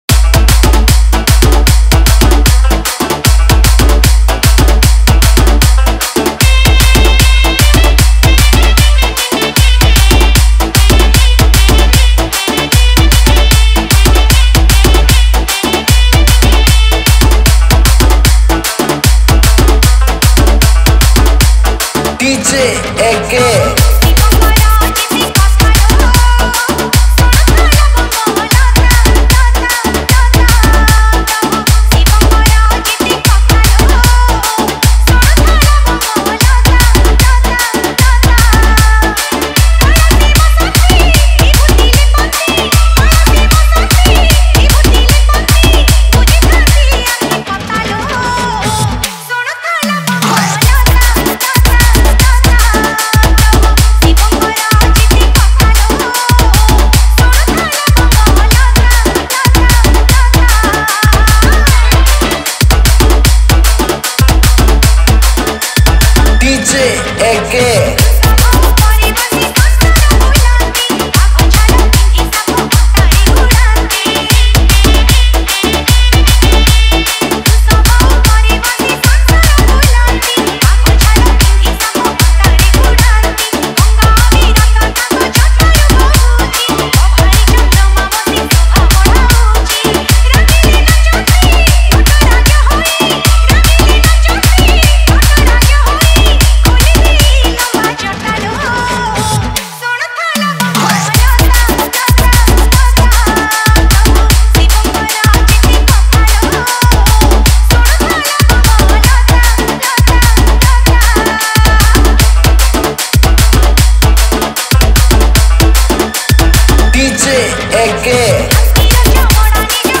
Category:  Odia Bhajan Dj 2021